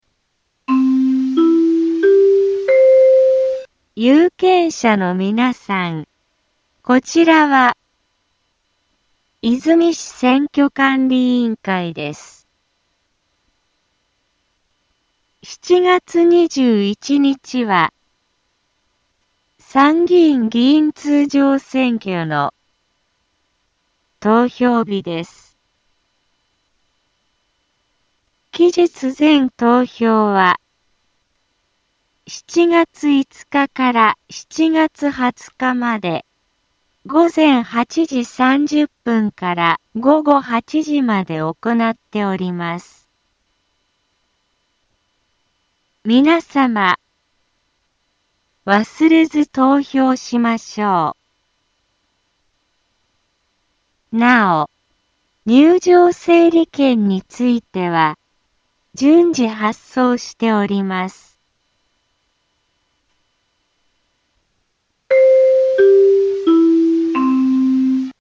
Back Home 災害情報 音声放送 再生 災害情報 カテゴリ：通常放送 住所：大阪府和泉市府中町２丁目７−５ インフォメーション：有権者のみなさん こちらは、和泉市選挙管理委員会です。 7月21日は、参議院議員通常選挙の、投票日です。